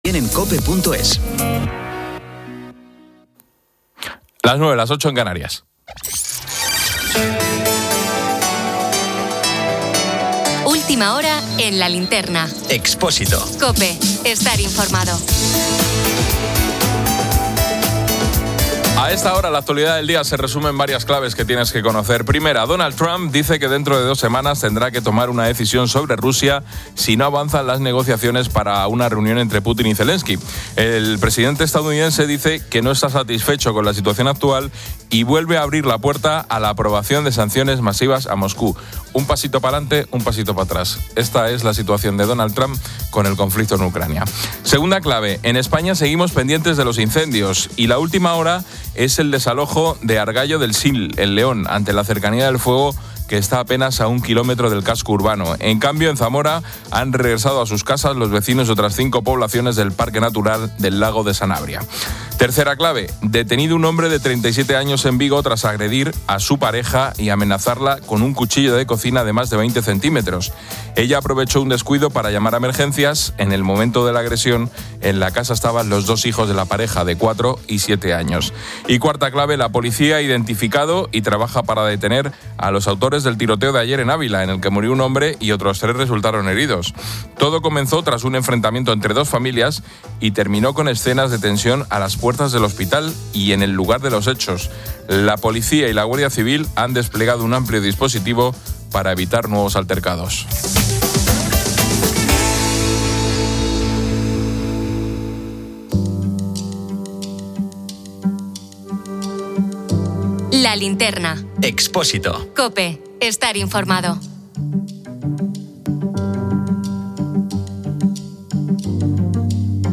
Una psicóloga explica las motivaciones (búsqueda de atención, dinero, identidad) y los riesgos de normalización de la violencia, especialmente en jóvenes.